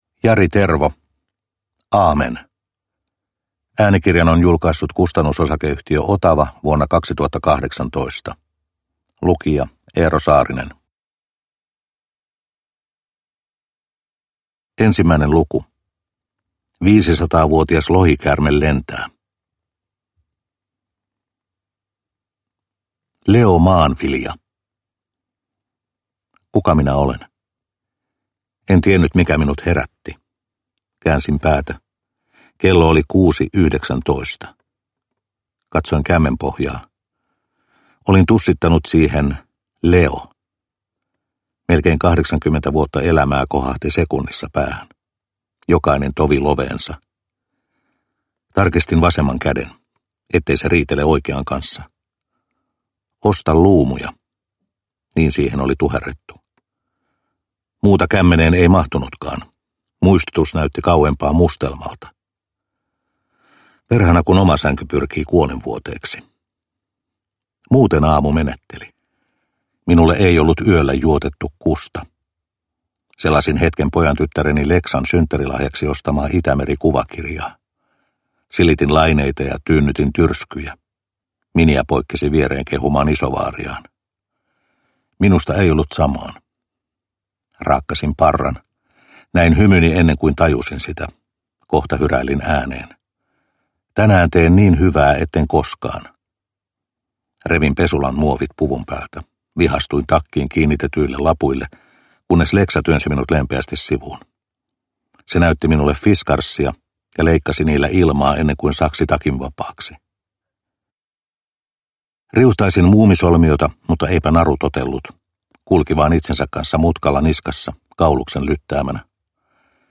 Aamen – Ljudbok – Laddas ner